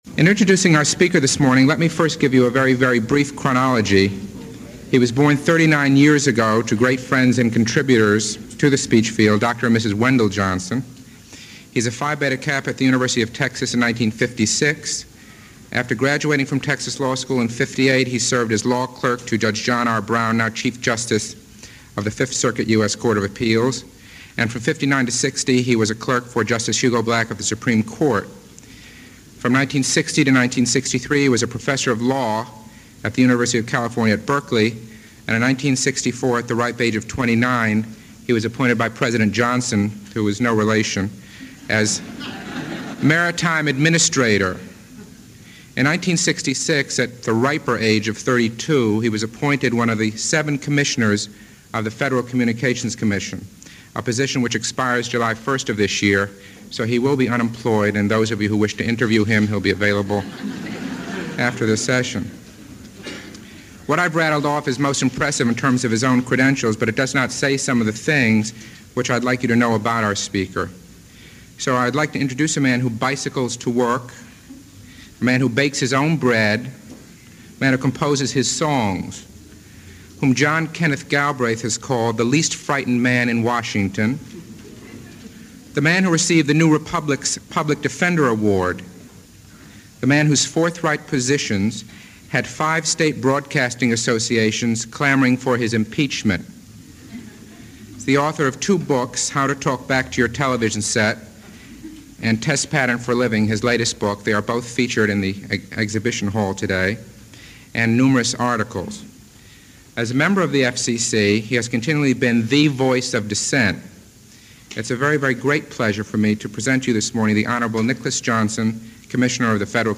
FCC-Chairman-Nicholas-Johnson-address-March-9-1973-processed.mp3